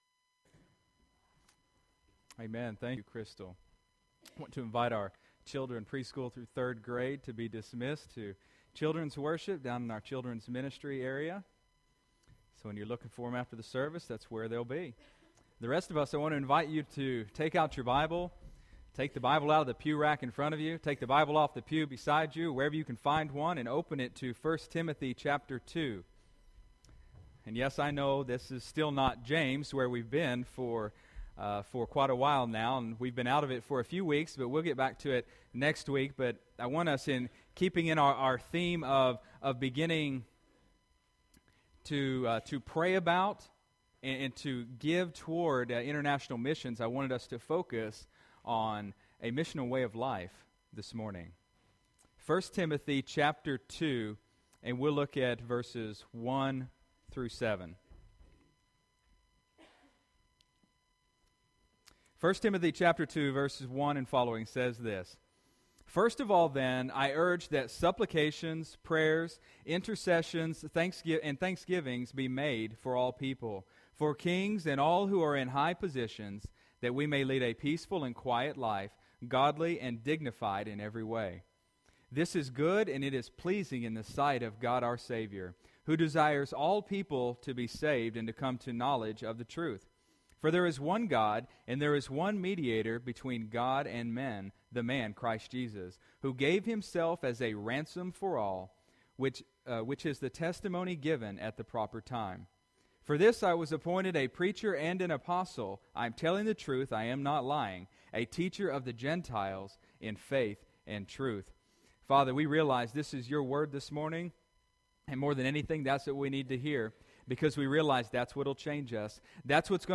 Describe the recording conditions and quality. Sunday, November 30, 2014 (Sunday Morning Service)